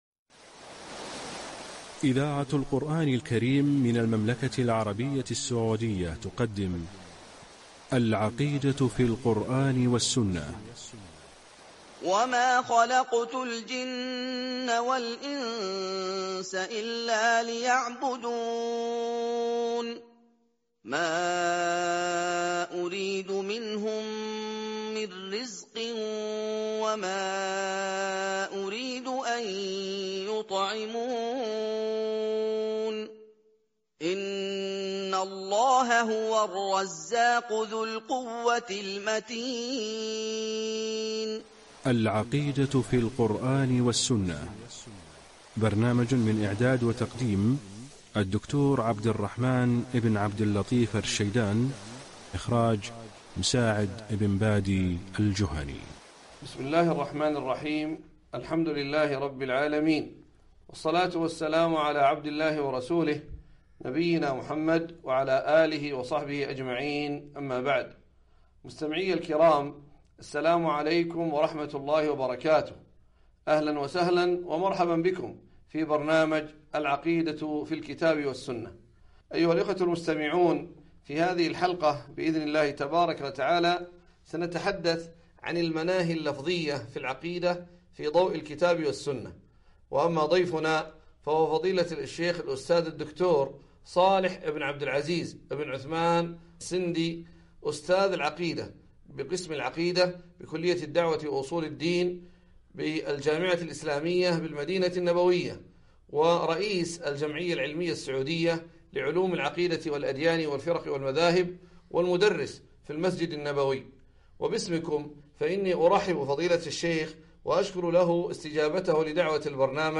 برنامج إذاعي عبر إذاعة القرآن الكريم المملكة العربية السعودية